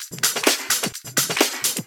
Index of /VEE/VEE Electro Loops 128 BPM
VEE Electro Loop 478.wav